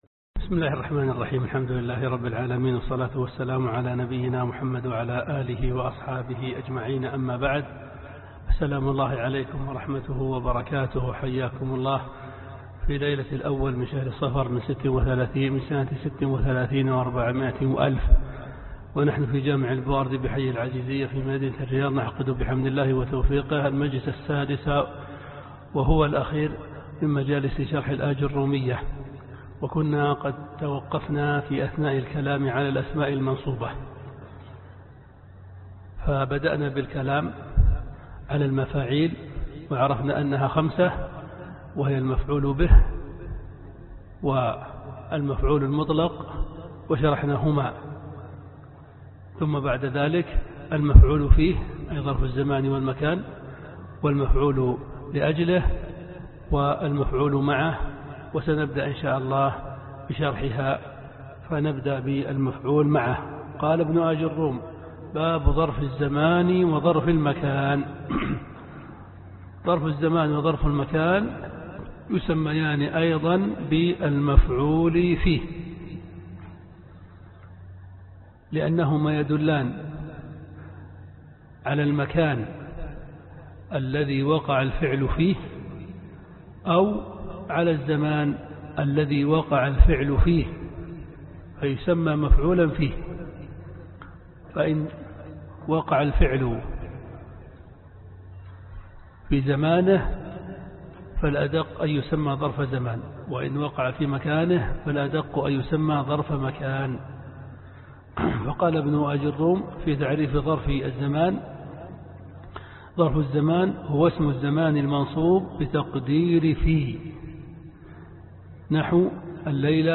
الدرس السادس (شرح متن الأجرومية في النحو)